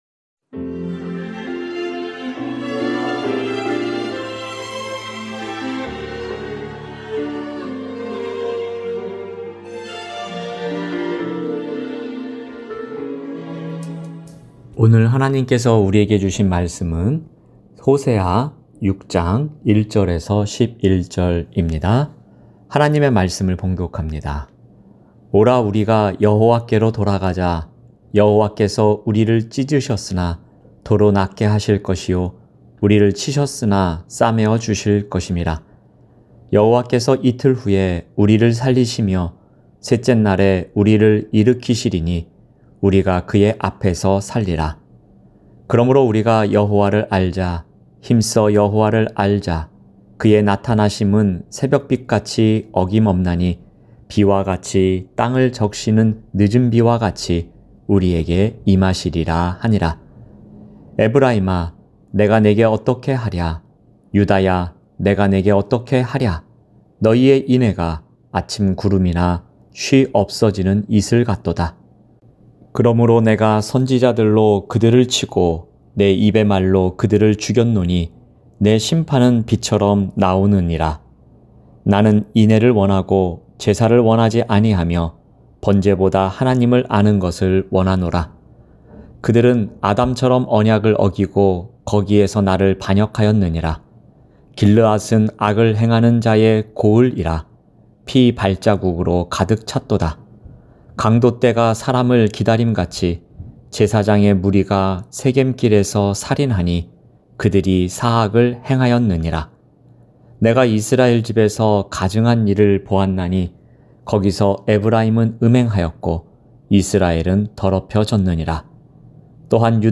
새벽설교